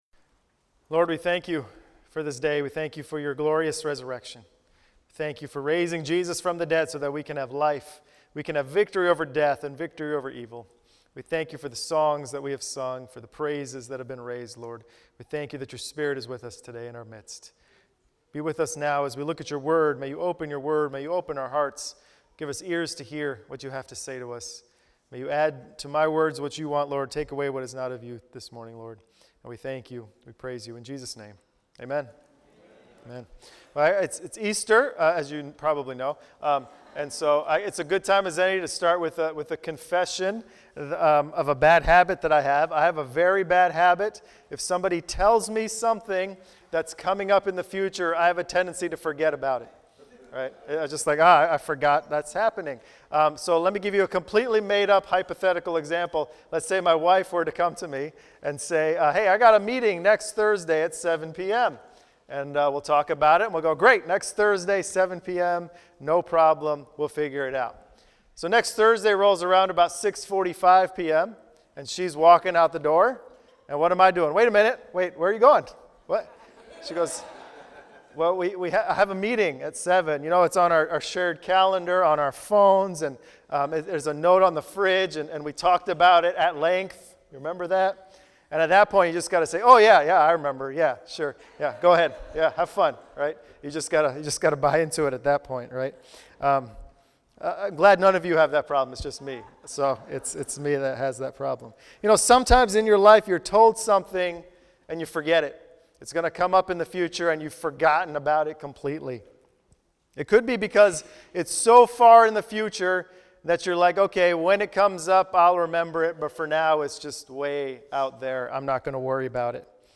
Messiah-Sermon-Easter-2019.mp3